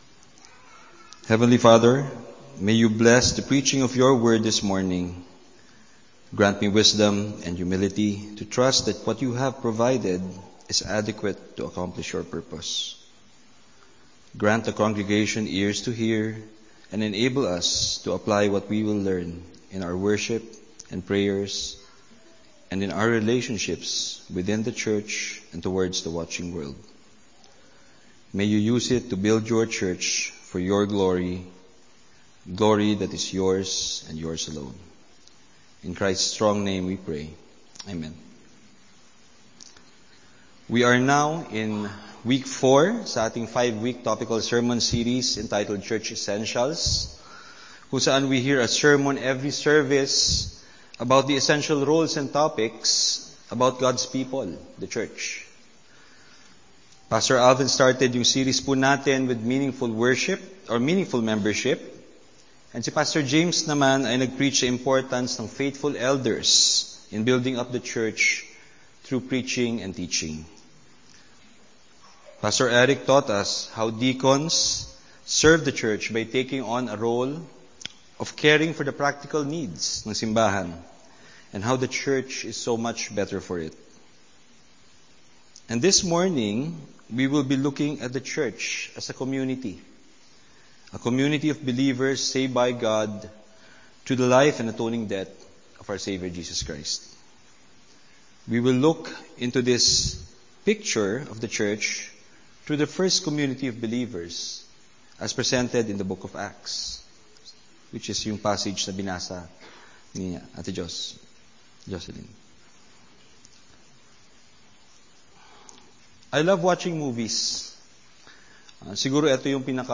A message from the series "Church Essentials."